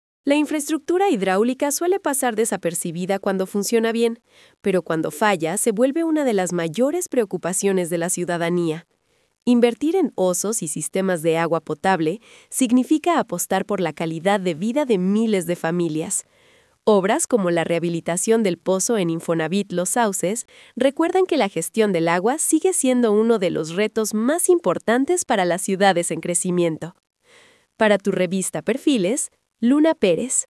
🎤 COMENTARIO EDITORIAL